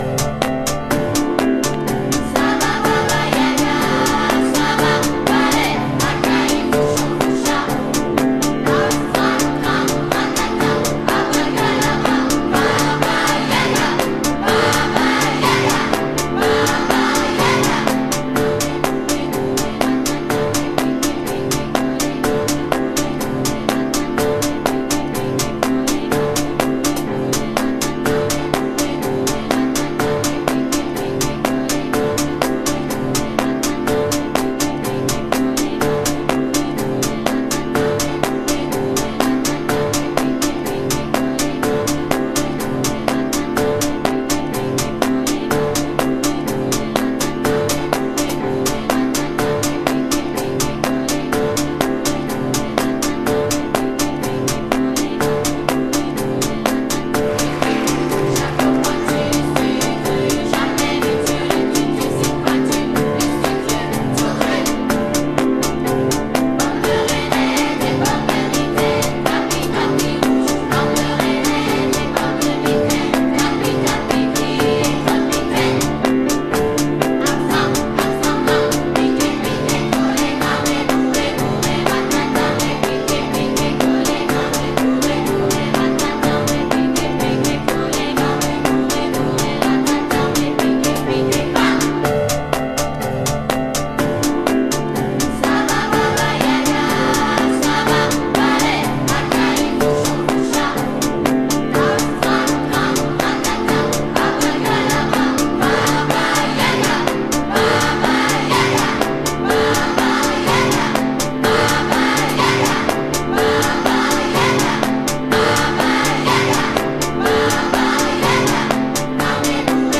House / Techno
キッズ・ヴォイスが延々とループするミニマルミュージック。